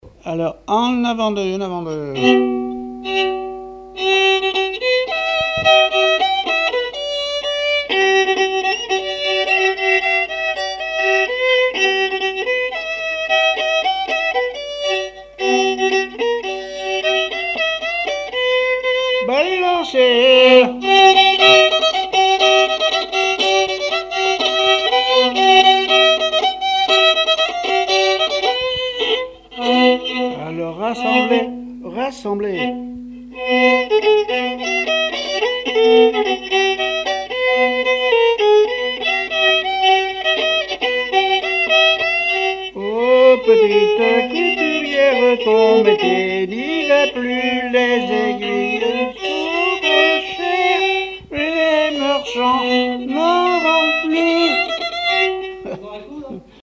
Mémoires et Patrimoines vivants - RaddO est une base de données d'archives iconographiques et sonores.
danse : branle : avant-deux
répertoire musical au violon
Pièce musicale inédite